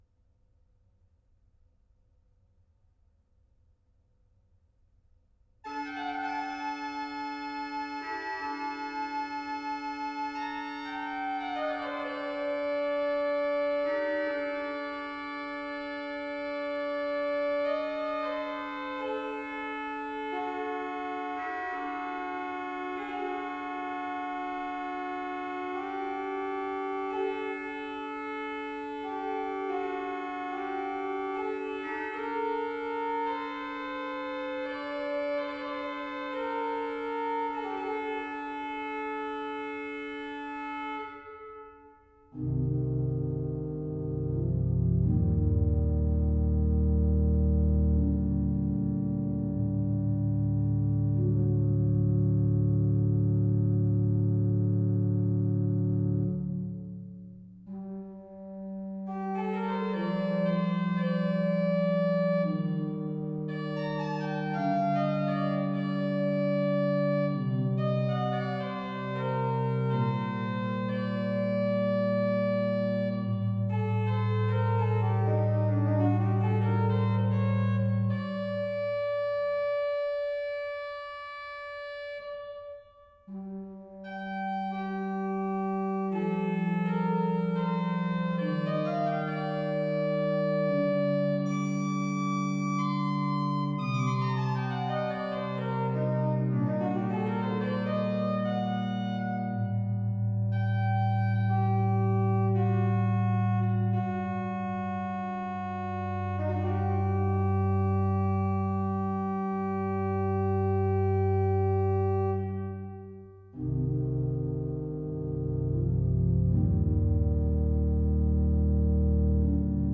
organ Download PDF Duration